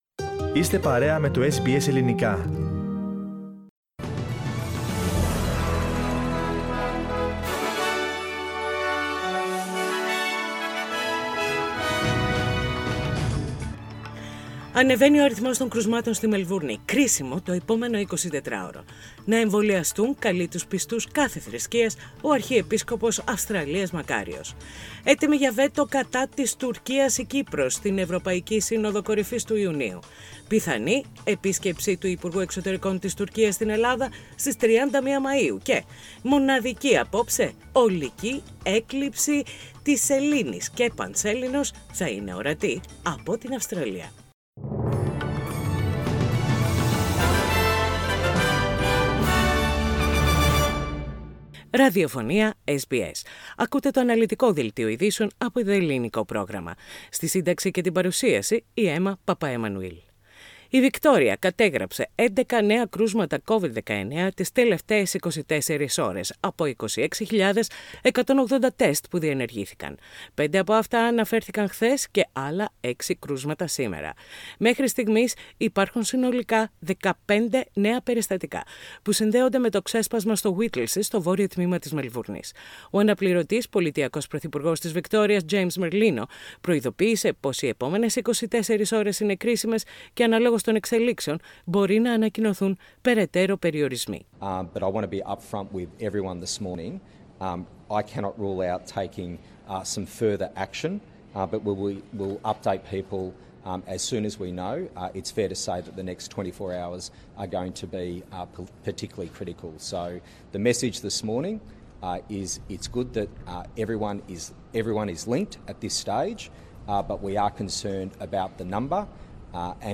Οι κυριότερες ειδήσεις της ημέρας από το Ελληνικό πρόγραμμα της ραδιοφωνίας SBS.